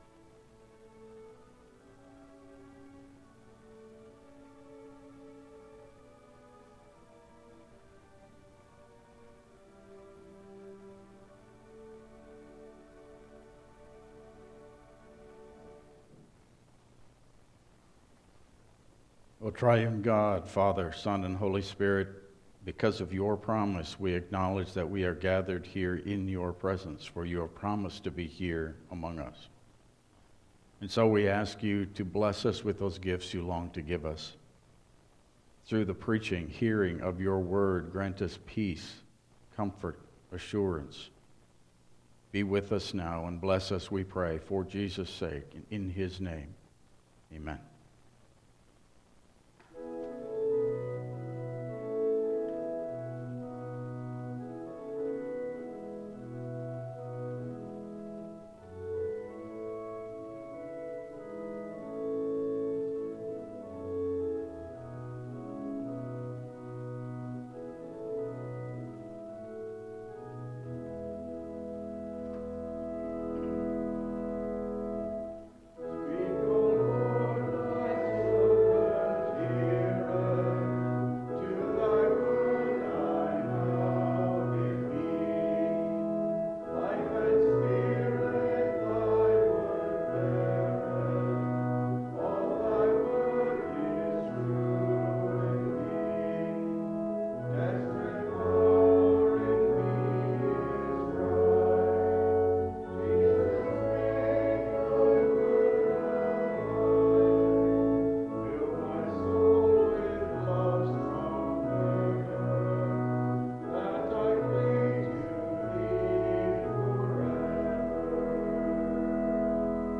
Passage: Ephesian 2:11-22 Service Type: Regular Service